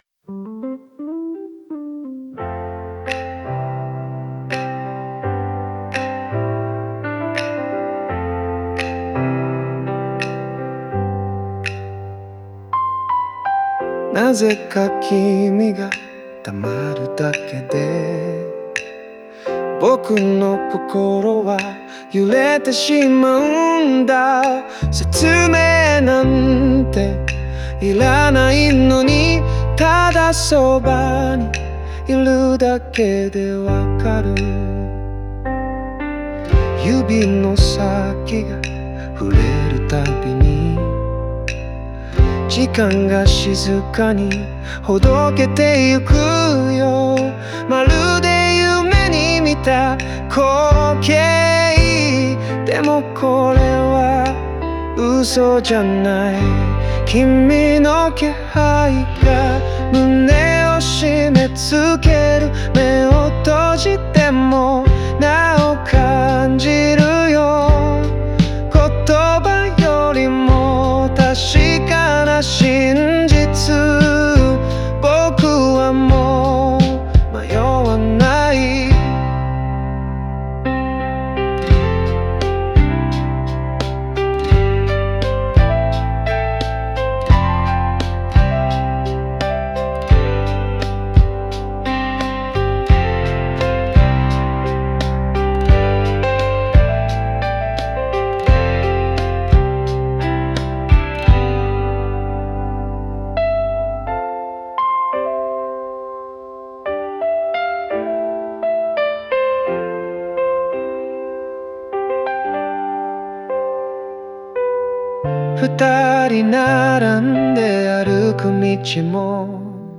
相手がそばにいるだけで満たされていく心の動きを、柔らかなメロディにのせて紡いでいきます。